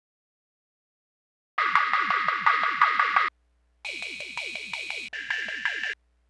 drums07.wav